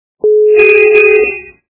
» Звуки » Авто, мото » сигнал авто - Жигули
При прослушивании сигнал авто - Жигули качество понижено и присутствуют гудки.
Звук сигнал авто - Жигули